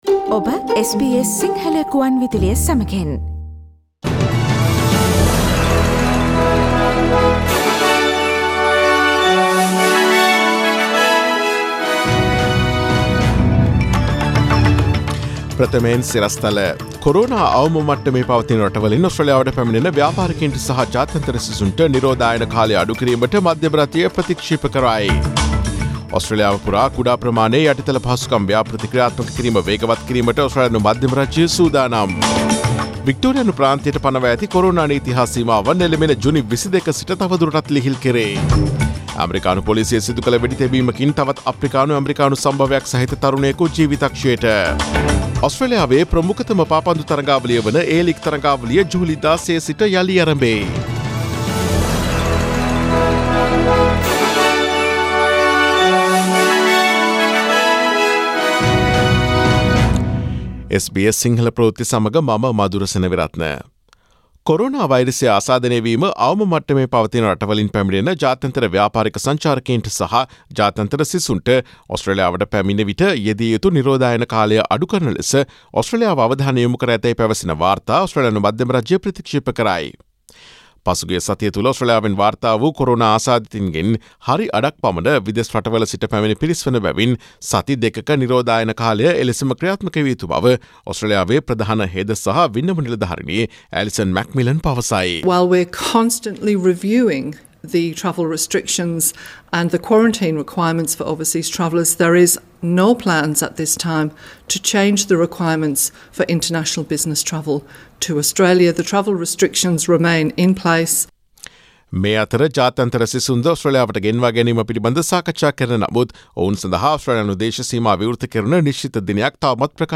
Daily News bulletin of SBS Sinhala Service: Monday 15 June 2020